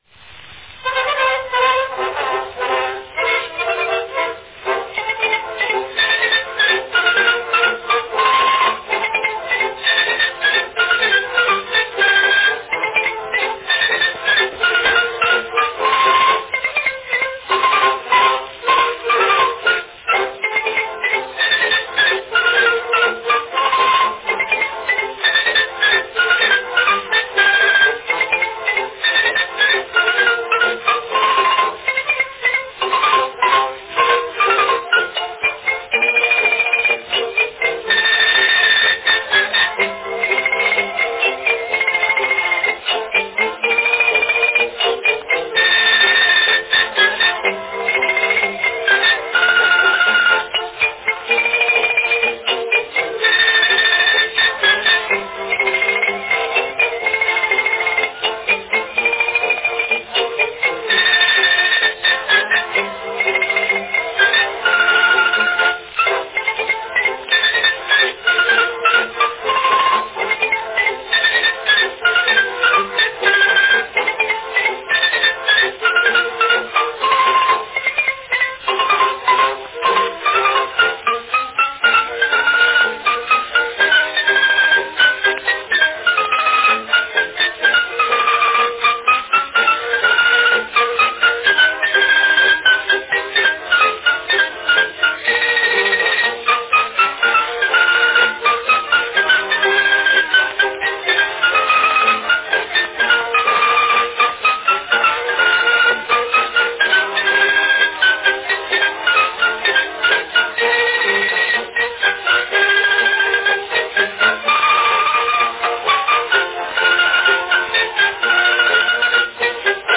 Listen to a complete two-minute wax cylinder recording -- A new cylinder every month.